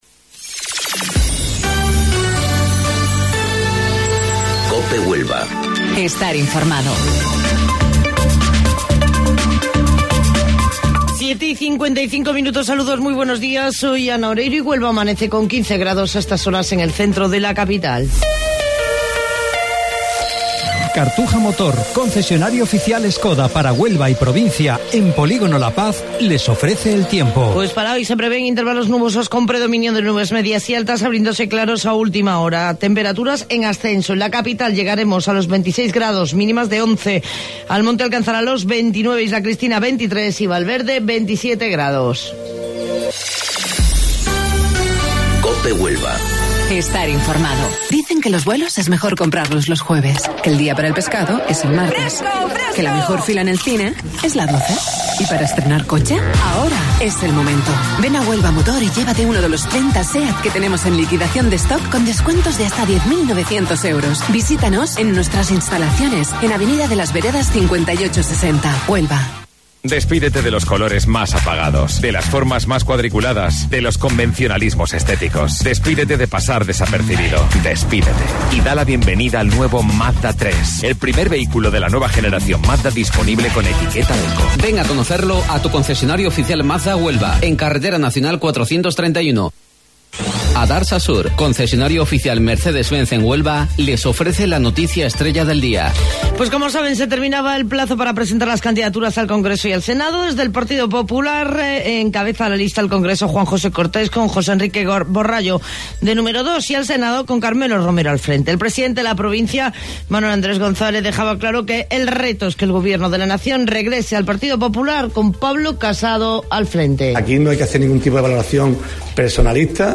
AUDIO: Informativo Local 07:55 del 26 de Marzo